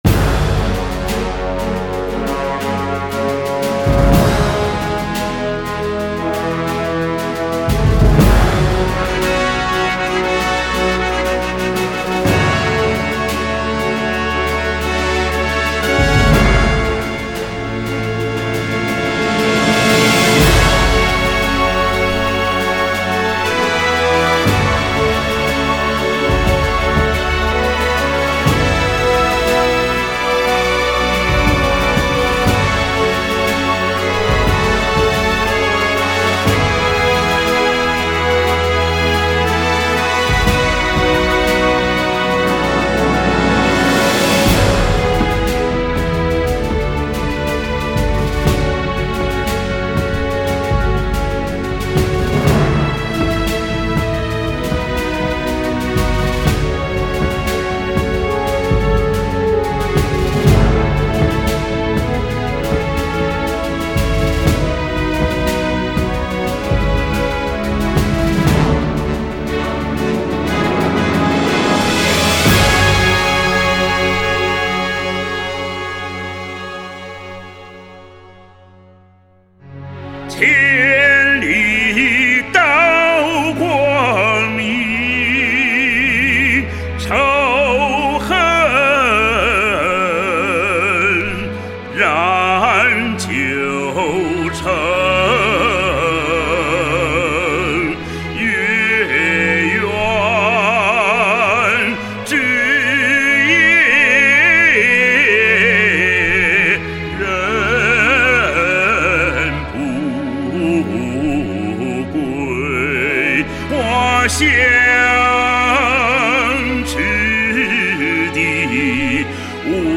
著名男中音歌唱家倾情演绎，终极人声发烧。
鼓的醇厚男中音，嗓音宽厚洪亮、稳健
录音制作精细、低频量感十足，音场